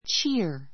cheer 中 A2 tʃíə r チ ア 動詞 声援 せいえん する , 喝采 かっさい する, 元気づける[づく] ⦣ 「いいぞ」「頑張 がんば れ」と大きな声を掛 か けてほめたり励 はげ ましたりすること.